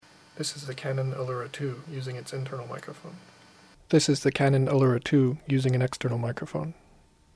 I didn't expect much for sound quality, due to the extremely compact form factor, and I wasn't much disappointed.
I am speaking quietly in my living room, at first to the built-in mic about one foot away, second through an external Sony ECM-MS908C (stereo condenser mic, $100) about six inches away.